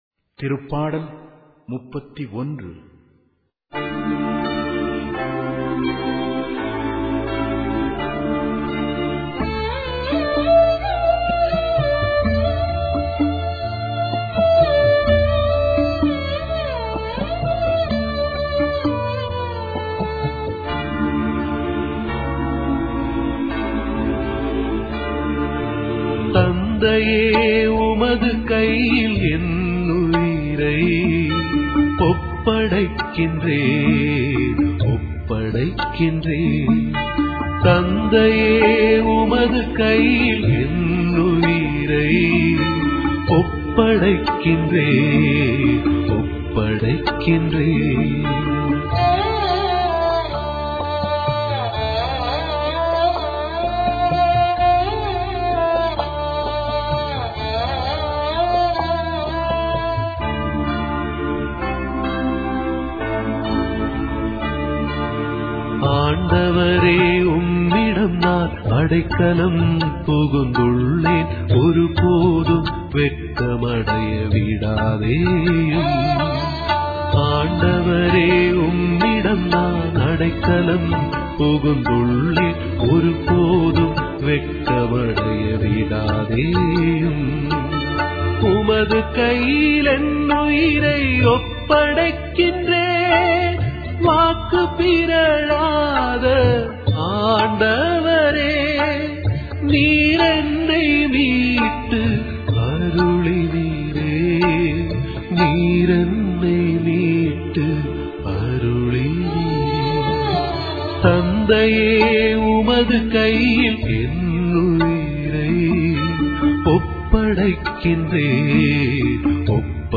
Directory Listing of mp3files/Tamil/Liturgical Songs/Psalms II/ (Tamil Archive)